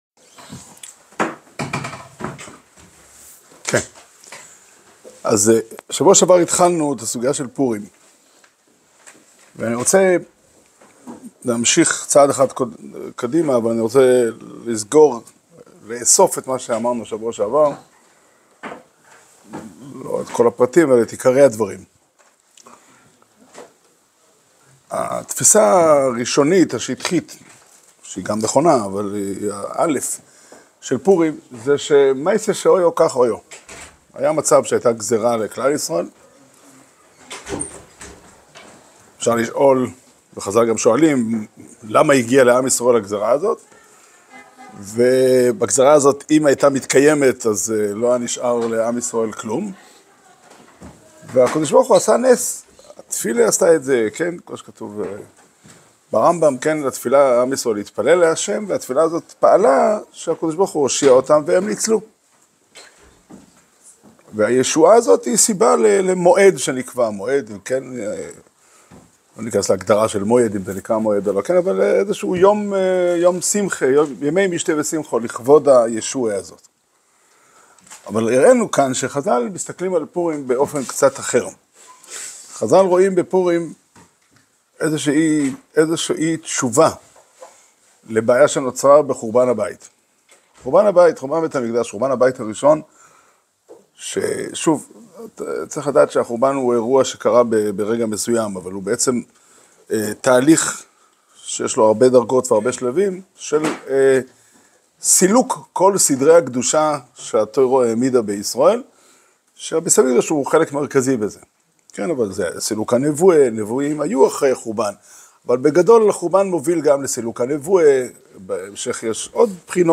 שיעור שנמסר בבית המדרש פתחי עולם בתאריך ו' אדר תשפ"ה